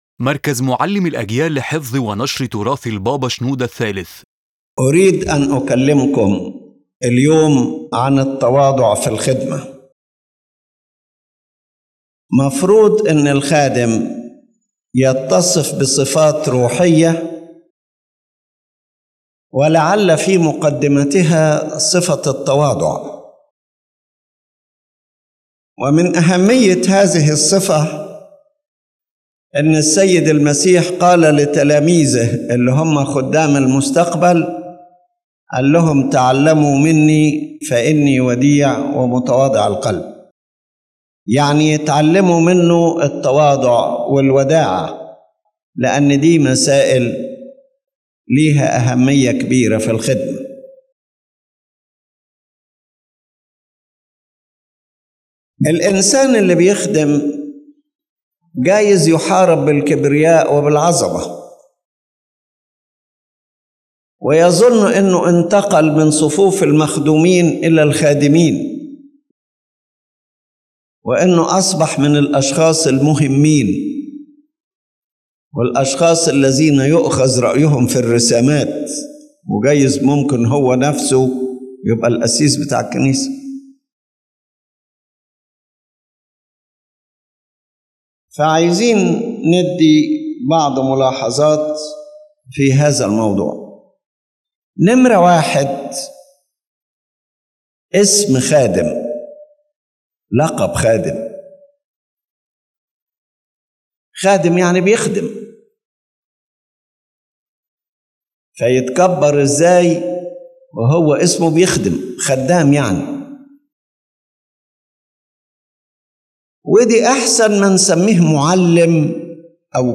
His Holiness Pope Shenouda III gives a lecture focusing on the necessity of humility in the life of the Christian servant. The talk explains that the name “servant” means that he serves, and that humility is one of the most important spiritual qualities that Christian service must possess.